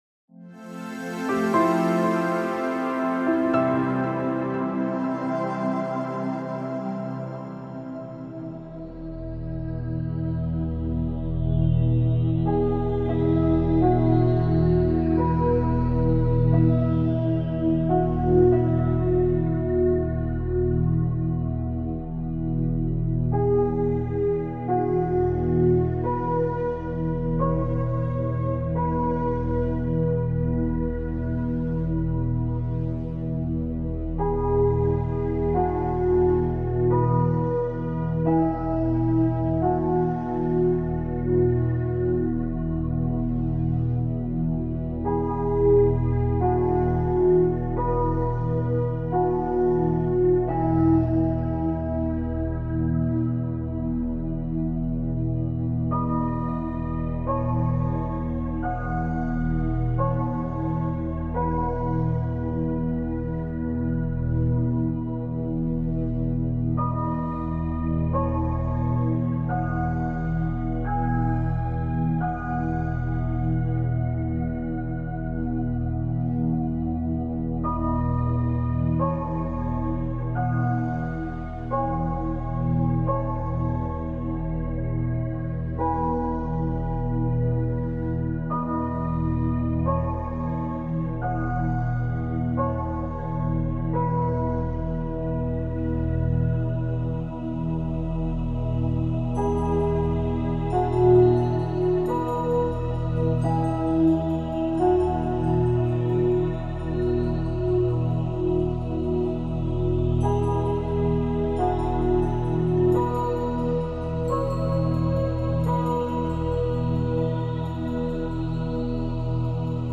Entspannende Musik mit 432 Hz und Silent Subliminals begleiten dich auf deinem Weg zum Erfolg.
Silent Subliminals enthalten spezielle Tonaufnahmen, die Affirmationen in einer Frequenz beinhalten, die jenseits der bewussten Hörfähigkeit des menschlichen Gehörs liegt.